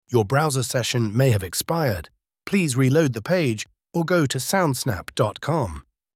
Ray Gun Sound: